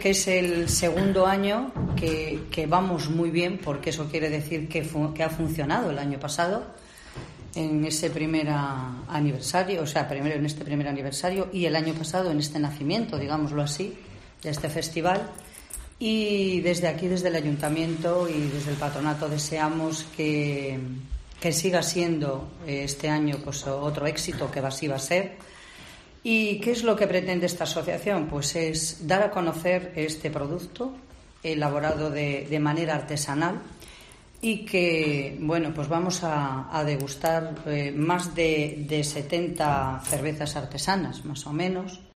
AUDIO: Declaraciones de la concejala de Fiestas de Ponferrada, Mari Crespo